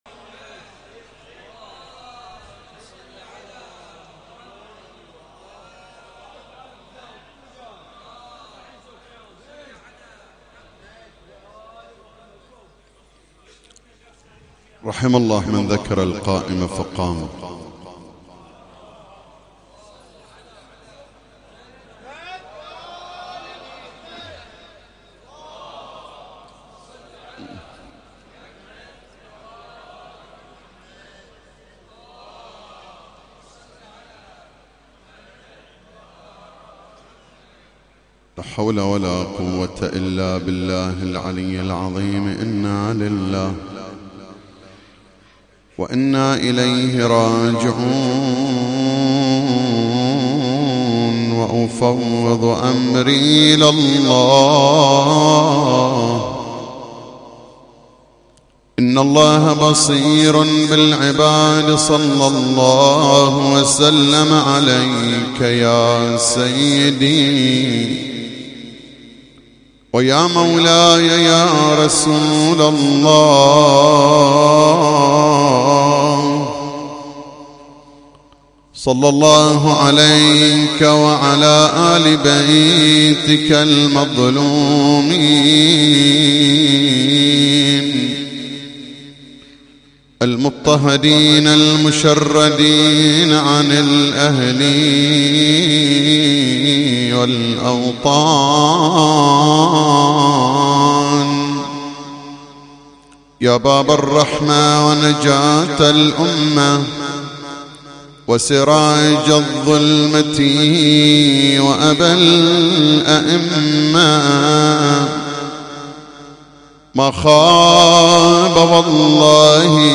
تغطية صوتية: ليلة سادس محرم 1440هـ